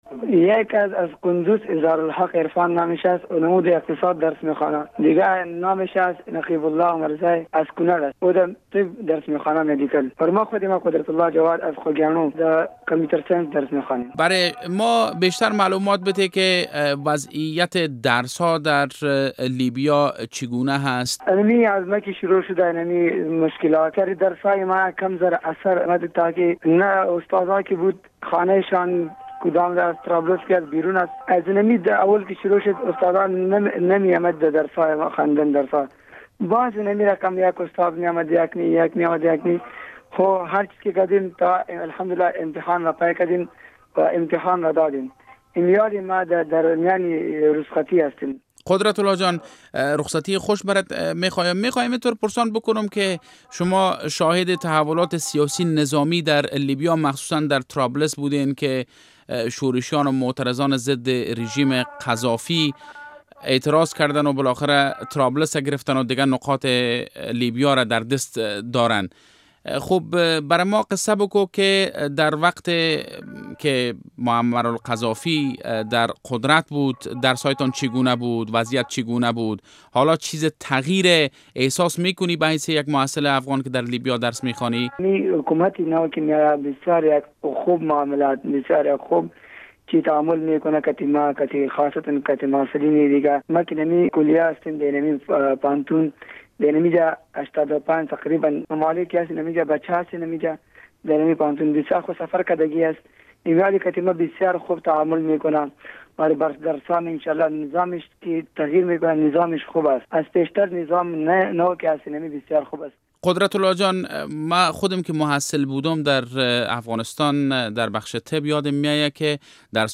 مصاحبه با یک محصل جوان افغان در شهر طرابلس لیبیا